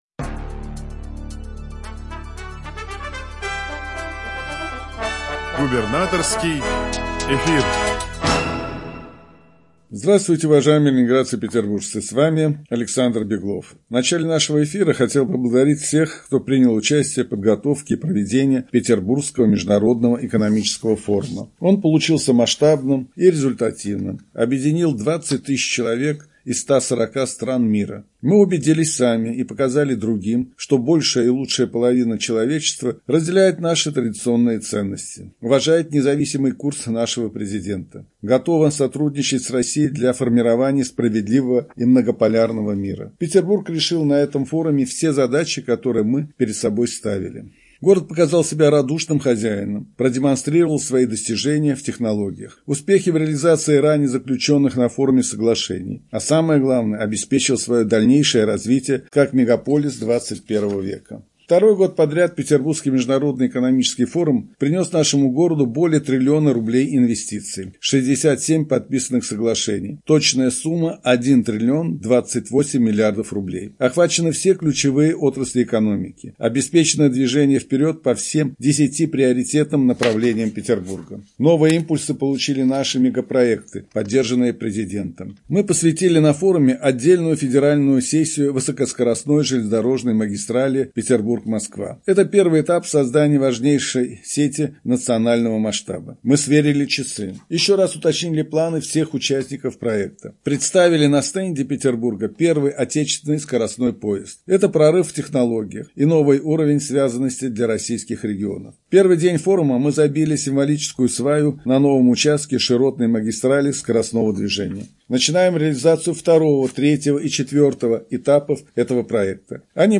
Радиообращение – 23 июня 2025 года